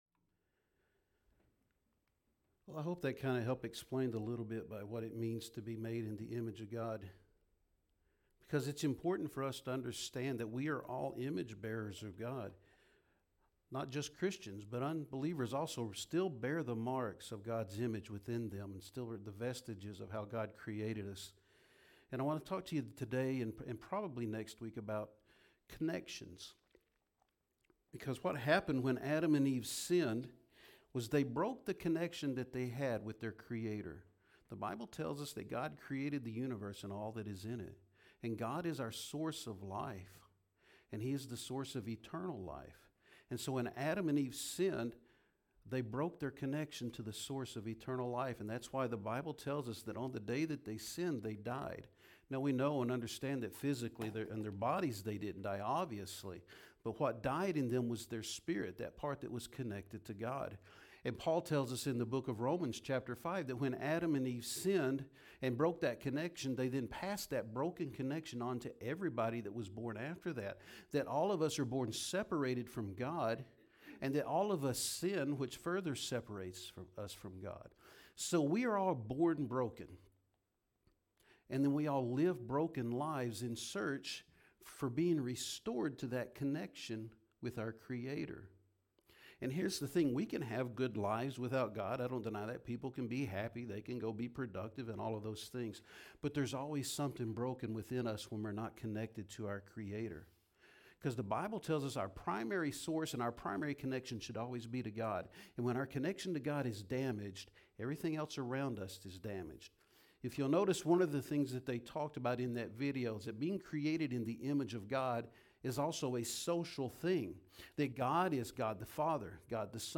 Sermons | Summitville First Baptist Church